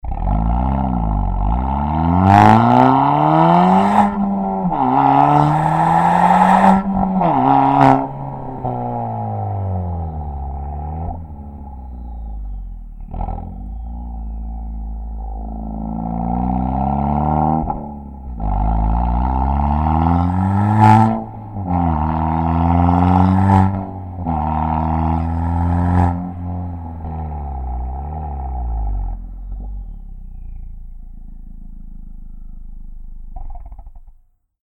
CAT-BACK-SYSTEM
KIA_Ceed_GT_REMUS_CAT_Back.mp3